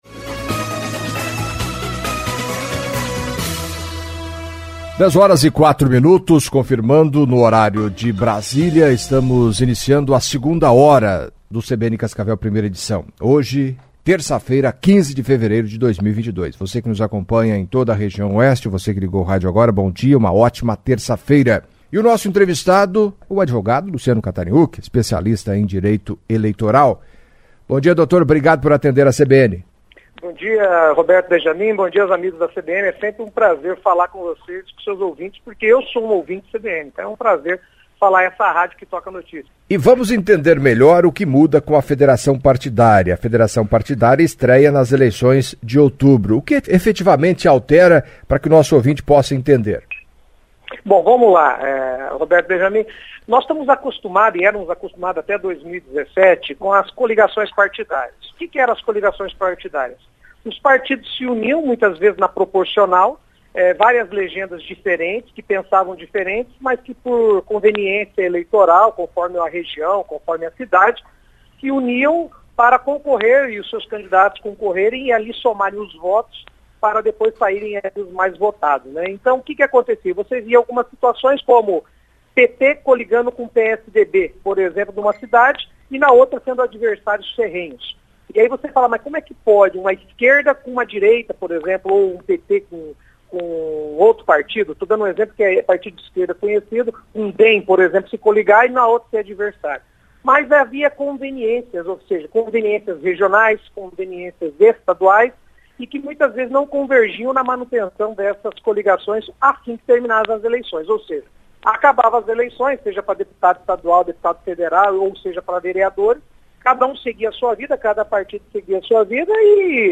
Em entrevista à CBN Cascavel nesta terça-feira (15)